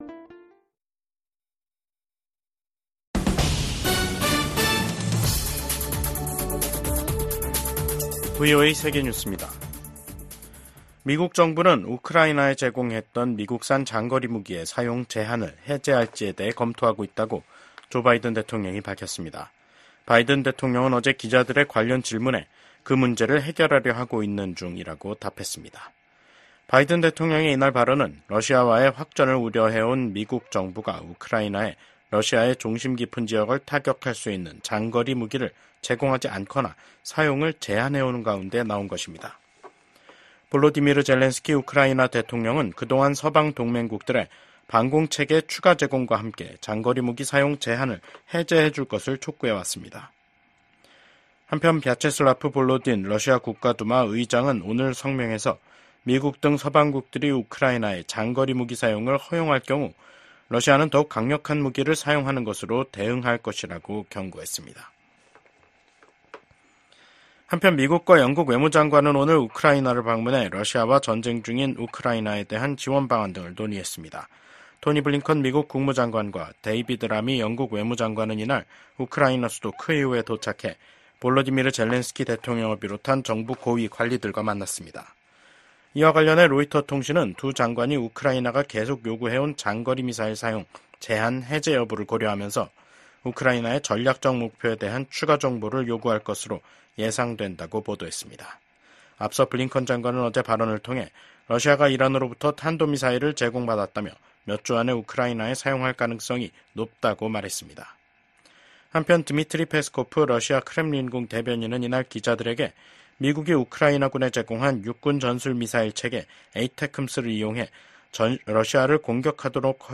VOA 한국어 간판 뉴스 프로그램 '뉴스 투데이', 2024년 9월 11일 2부 방송입니다. 오는 11월 대선에서 맞붙는 카멀라 해리스 부통령과 도널드 트럼프 전 대통령이 TV 토론회에 참석해 치열한 공방을 벌였습니다. 미국, 한국, 일본 간 협력 강화를 독려하는 결의안이 미 하원 본회의를 통과했습니다. 서울에선 68개 국가와 국제기구 고위 인사들이 참석한 가운데 다자 안보회의체인 서울안보대화가 열렸습니다.